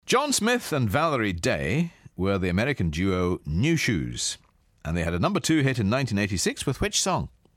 Here's Ken with today's hard PopMaster question.